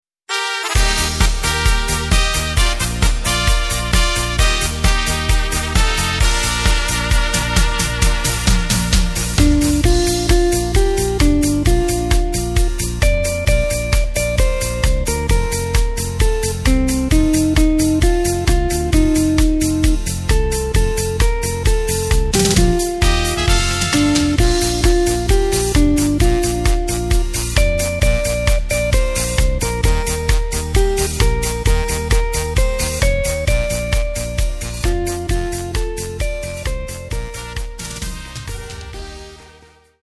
Takt:          4/4
Tempo:         132.00
Tonart:            Bb
Discofox aus dem Jahr 2022!
Playback mp3 Demo